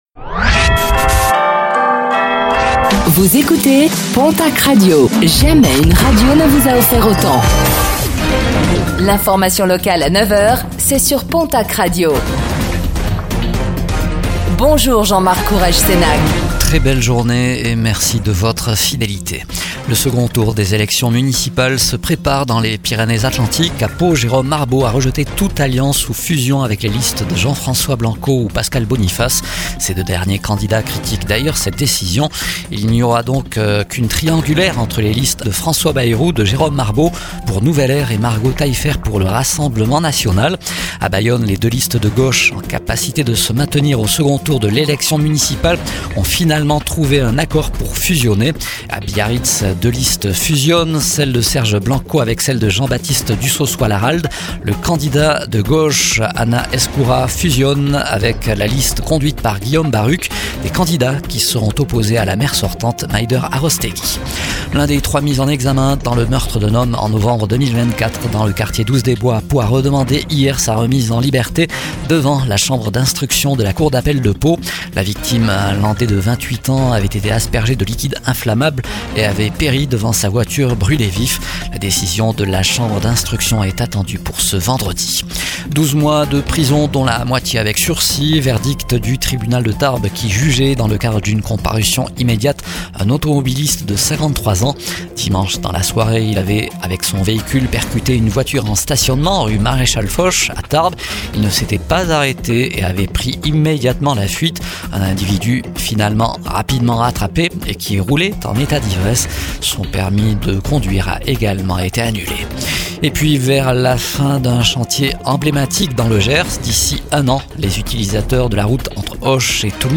Réécoutez le flash d'information locale de ce mercredi 18 mars 2026